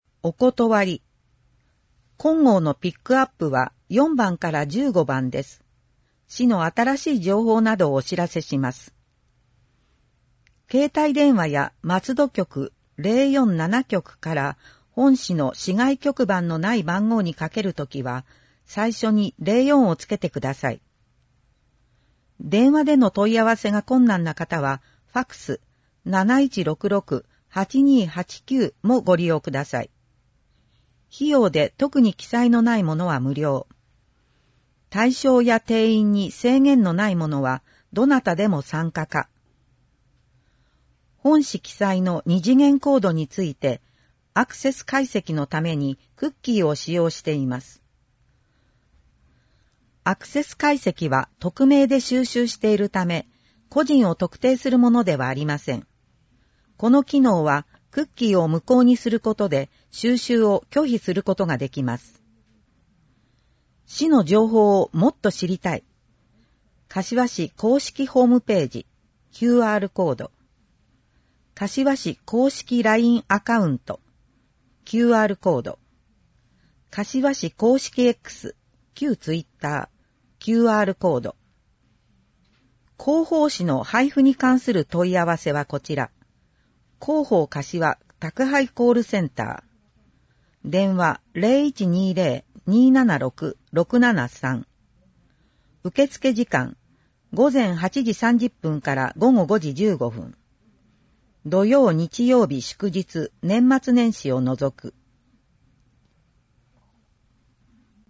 • 広報かしわの内容を音声で収録した「広報かしわ音訳版」を発行しています。
• 発行は、柏市朗読奉仕サークルにご協力いただき、毎号行っています。